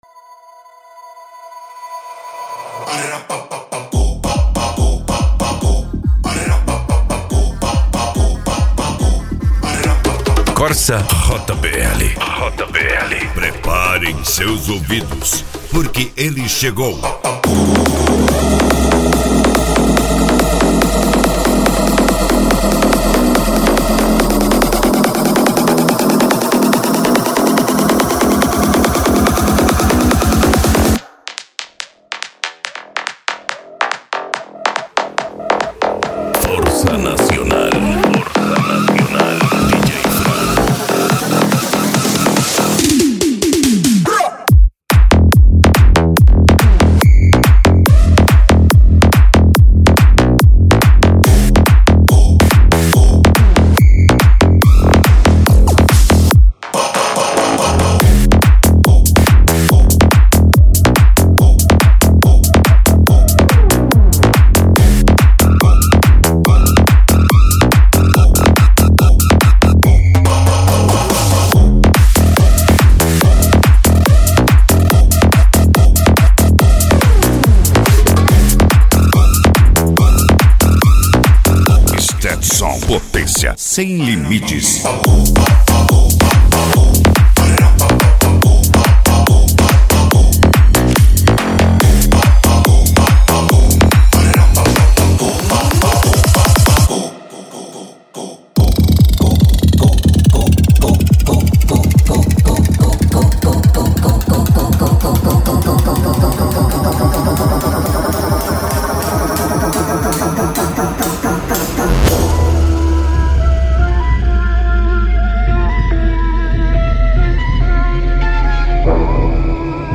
Eletronica
PANCADÃO
Psy Trance